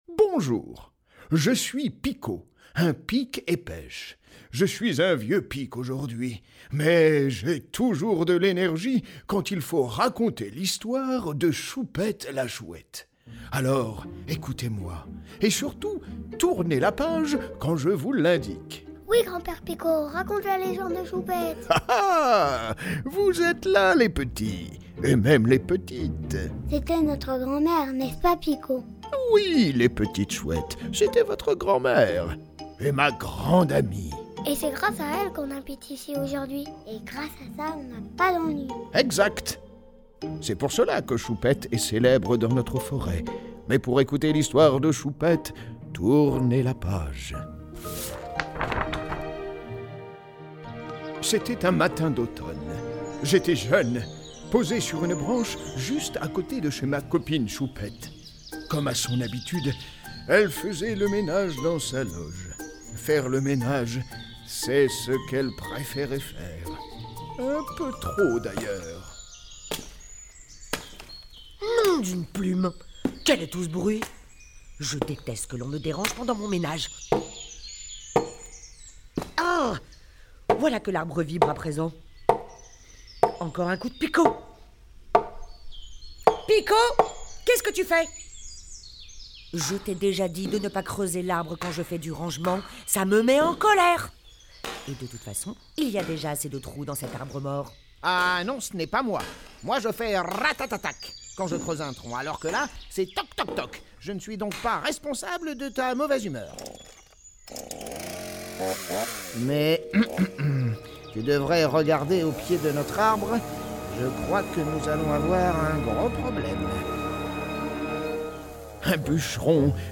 La légende de Choupette, un audio livre pour les enfants de 3 à 7 ans